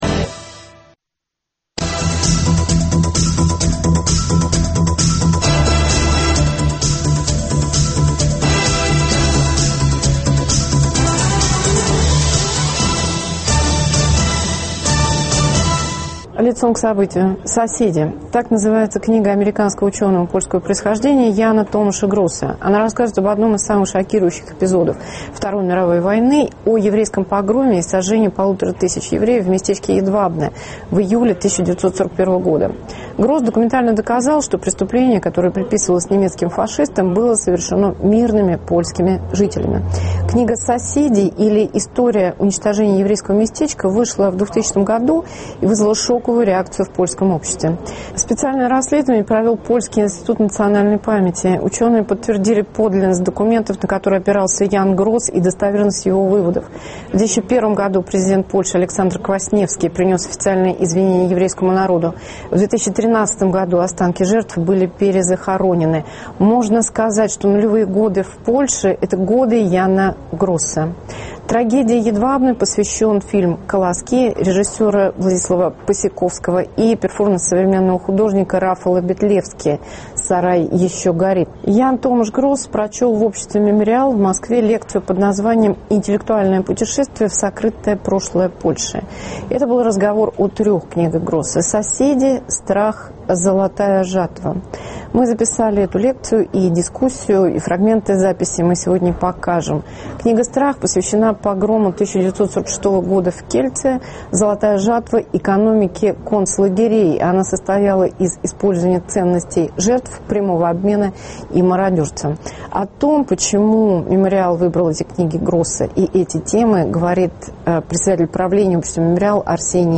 Московская лекция ученого, изменившего представление о Холокосте и знание поляков о себе. Поляки и евреи во время и после Второй мировой войны.
Ян Томаш Гросс в дискуссии с российскими интеллектуалами в Обществе “Мемориал” и в интервью Радио Свобода - об исторической ответственности и политическом воспитании элит.